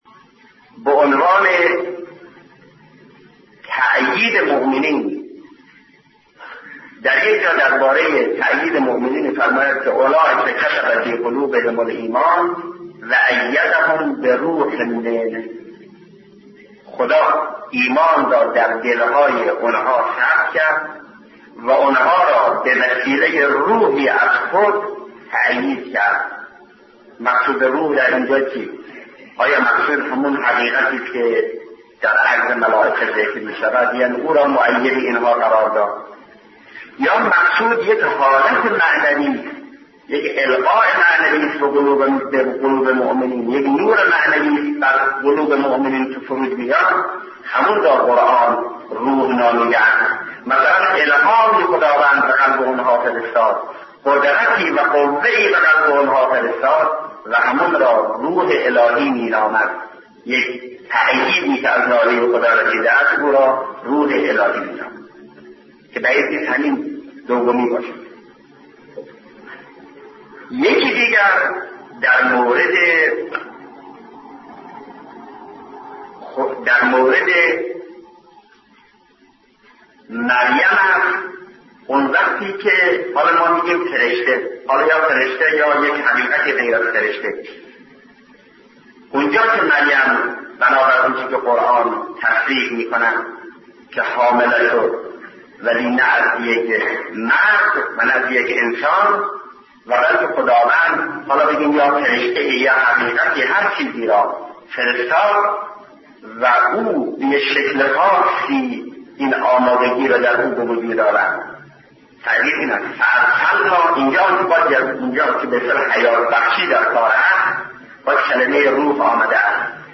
صدای شهید مطهری درباره وحی الهی به انسان 4/ خدا ایمان را دل های آن ها خلق کرد و آن ها را به وسیله روحی از خود تآیید کرد. مقصود از روح چیست؟